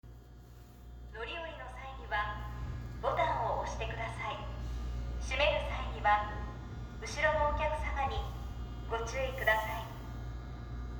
・20400系 ワンマン放送
【駅停車中】乗り降りの際には…